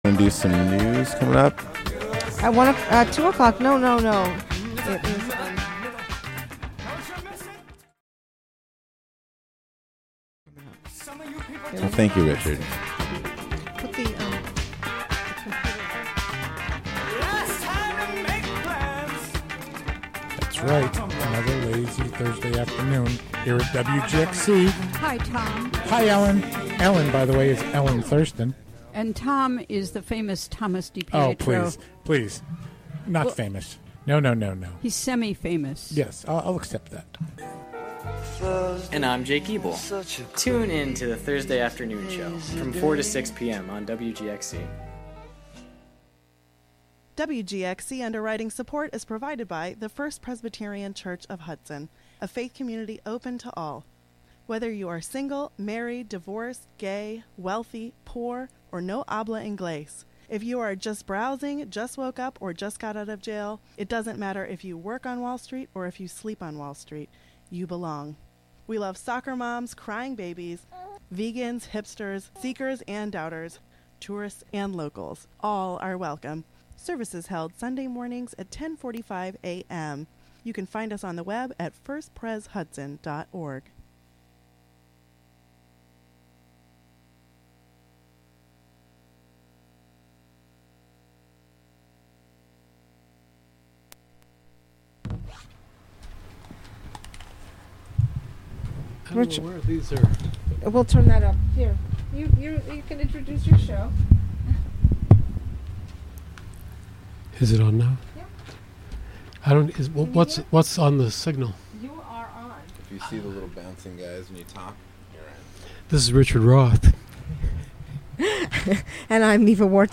Informal interviews with artists, writers and other creative people in the WGXC-FM broadcast area, and occasional talks on meditation or programs devoted entirely to music. Broadcast live from the Hudson studio.